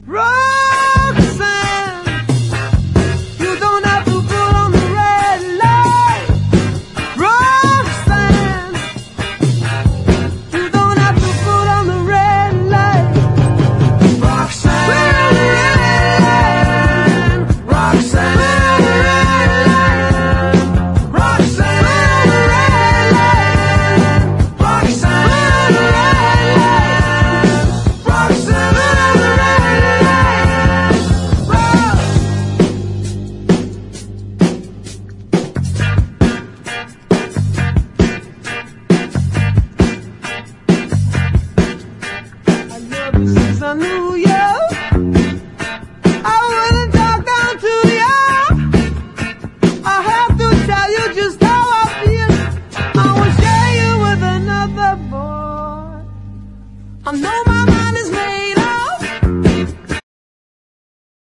HIP HOP/R&B / 80'S ROCK / NEW WAVE (UK)
こみあげパンキッシュ＆ホワイト・レゲエな歴史的名曲
インスト、アカペラを含む4ヴァージョンを収録。